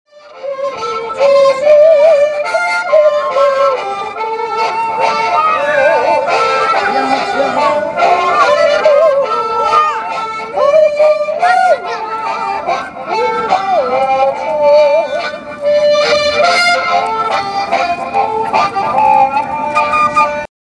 This guy had a special vest to haul around his harmonica collection (click here to listen to the
HarmonicaMan.mp3